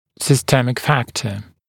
[sɪ’stemɪk ‘fæktə][си’стэмик ‘фэктэ]системный фактор